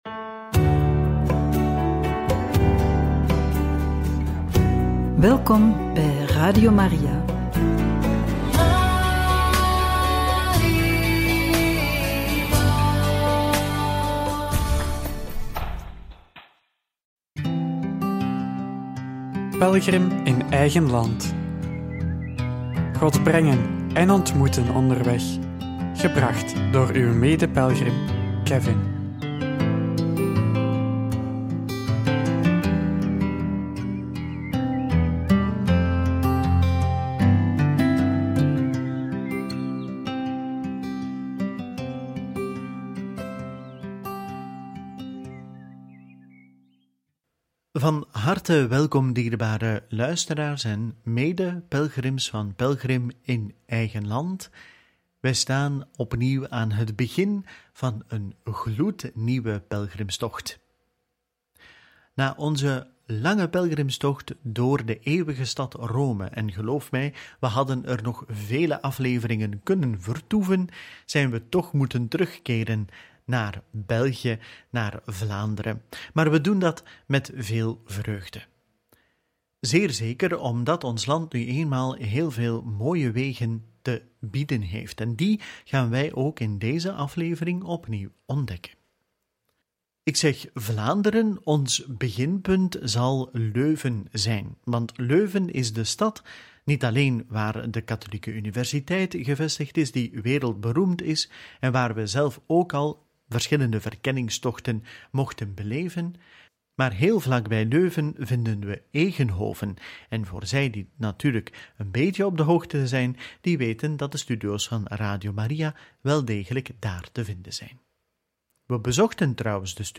Vredesbedevaart van Leuven naar Basse-Wavre Deel 1 – Parochie Onze Lieve Vrouw van Troost – Kerkhof van de Zusters Annunciaten – Radio Maria